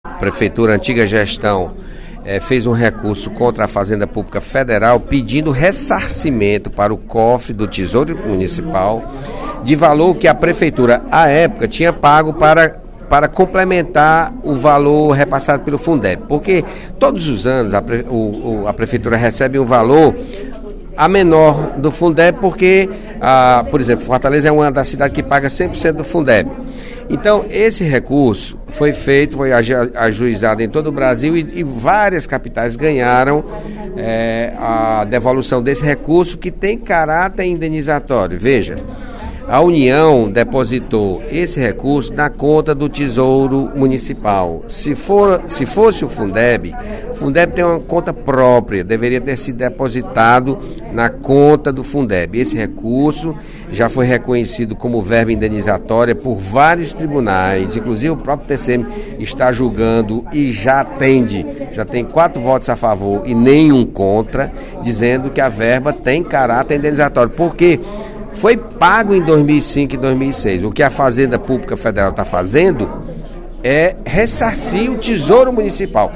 O deputado José Sarto (Pros) fez pronunciamento nesta quinta-feira (18/02), no primeiro expediente da sessão plenária, para explicar a legalidade da utilização, pela Prefeitura de Fortaleza, dos recursos da ordem de R$ 239 milhões, repassados pela União, do Fundo de Manutenção e Desenvolvimento da Educação Básica e de Valorização dos Profissionais da Educação (Fundeb), a título de verba indenizatória.